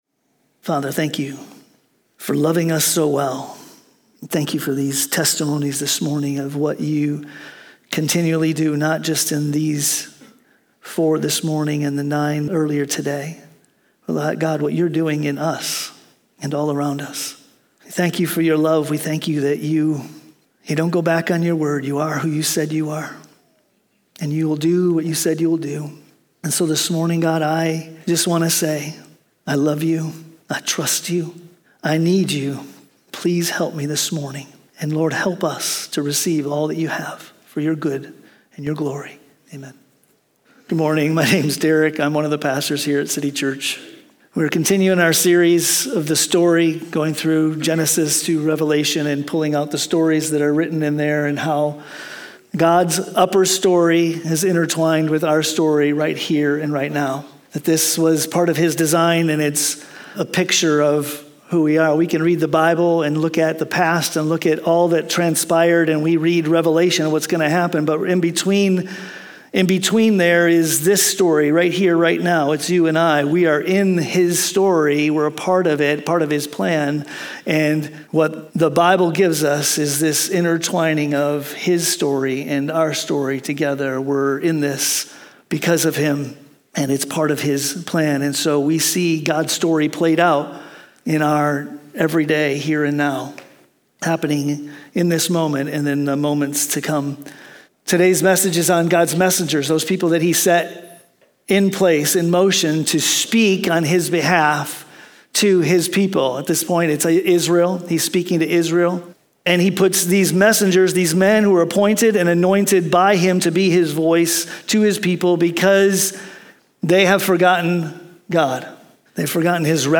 Sunday Messages @ City Church